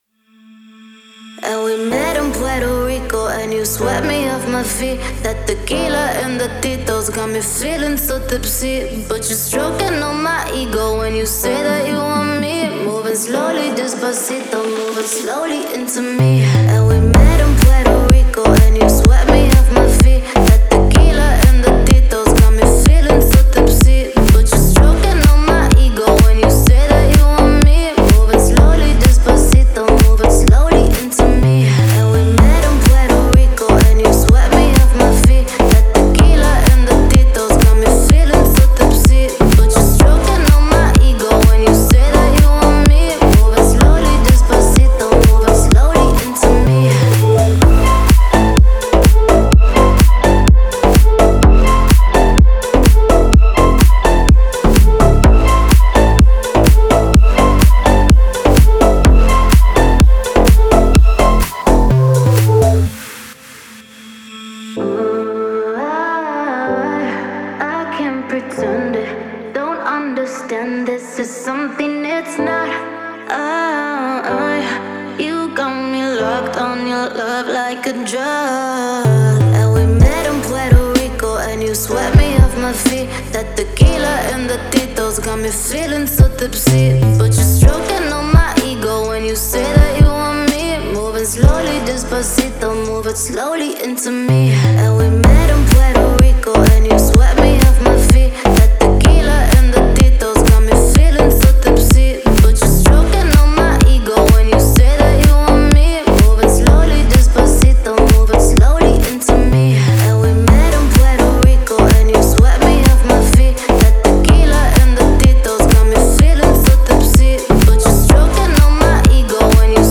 это яркая и зажигательная композиция в жанре EDM